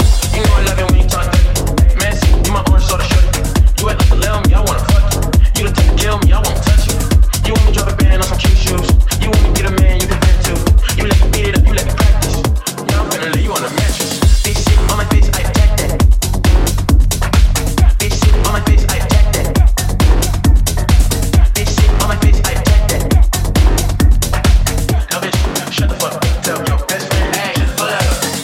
tech house hits
Genere: house,tec house,tecno,remix,hit